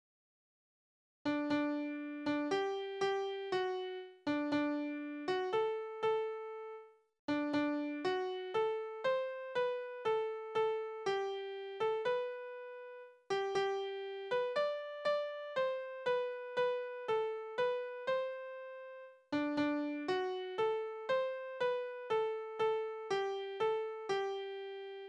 Tonart: G-Dur
Taktart: 3/4
Tonumfang: Oktave
Besetzung: vokal
Anmerkung: Vortragsbezeichnung: lebhaft